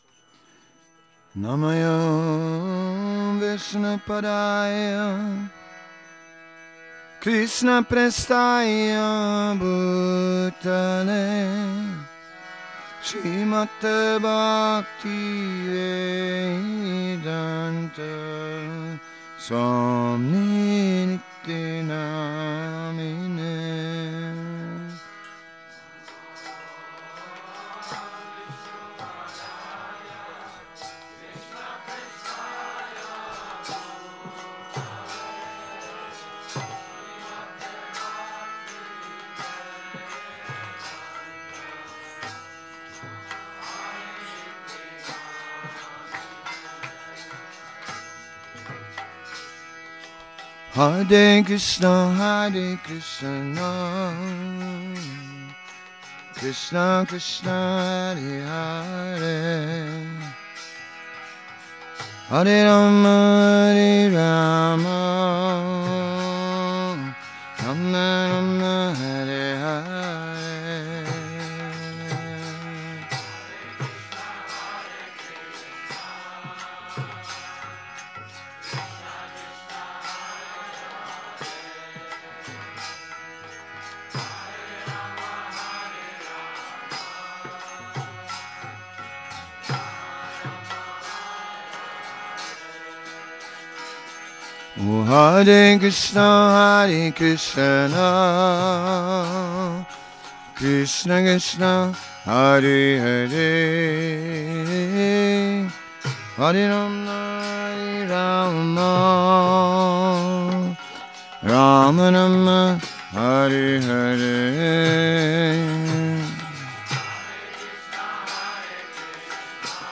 Šrí Šrí Nitái Navadvípačandra mandir
Kírtan Nedělní program